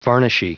Prononciation du mot varnishy en anglais (fichier audio)
Prononciation du mot : varnishy